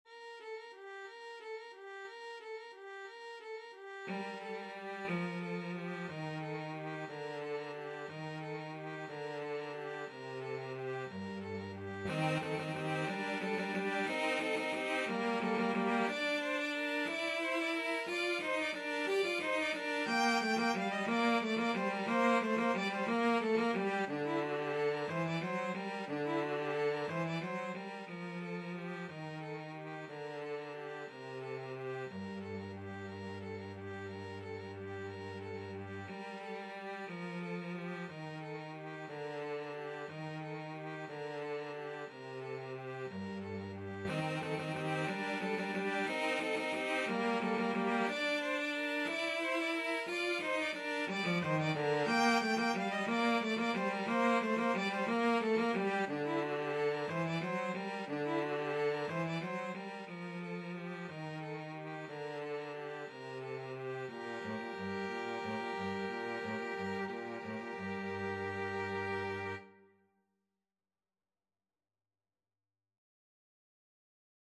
Free Sheet music for Violin-Cello Duet
3/4 (View more 3/4 Music)
G minor (Sounding Pitch) (View more G minor Music for Violin-Cello Duet )
Violin-Cello Duet  (View more Intermediate Violin-Cello Duet Music)
Traditional (View more Traditional Violin-Cello Duet Music)